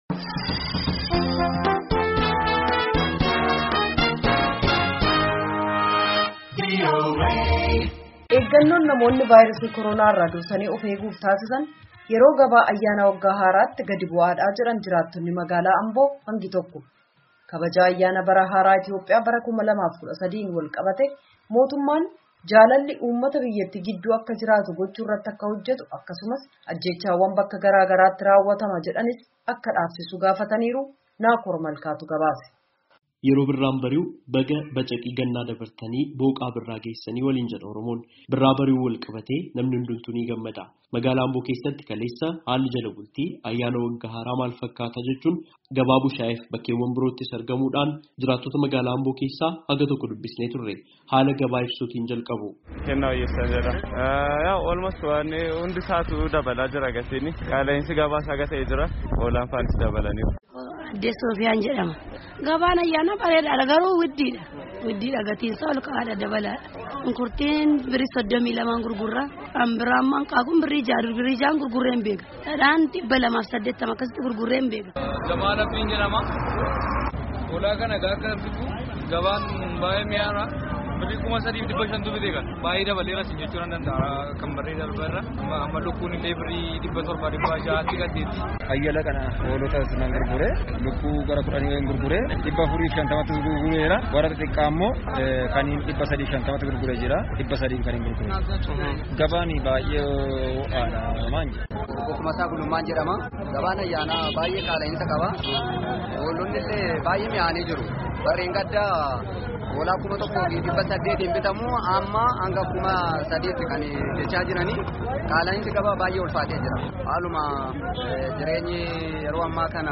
Haalli Ayyaana Waggaa Haaraa Bara Kanaa Itti Ayyaaneffame Ka Dur Baratame Irraa Adda, Jedhu Namoonni Ambo Irraa VOAf Yaada Kennan
Yaada namootaa Ambo irraa walitt-qabame caqasaa.